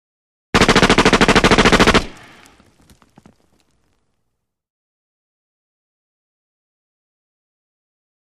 9 mm UZI Automatic Fire 1; Debris And Dirt Fall.